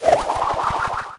spike_atk_01.ogg